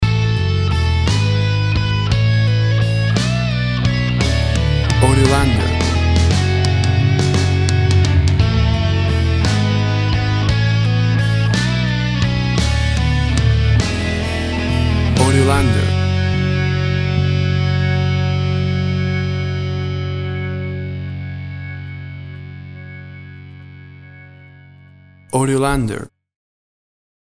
A big and powerful rocking version
WAV Sample Rate 16-Bit Stereo, 44.1 kHz
Tempo (BPM) 175